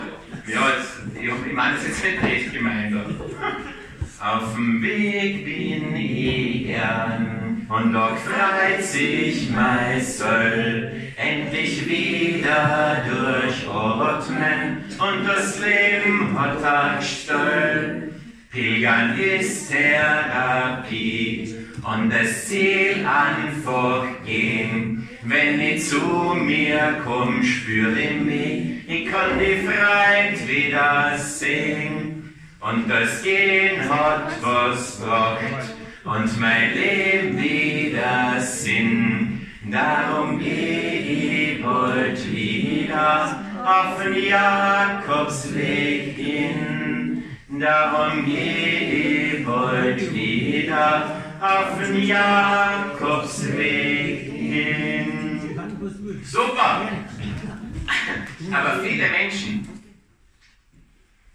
IHS im Kabarett